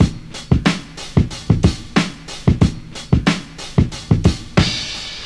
Free breakbeat - kick tuned to the E note. Loudest frequency: 2046Hz
• 91 Bpm 00's Breakbeat E Key.wav
91-bpm-00s-breakbeat-e-key-gdJ.wav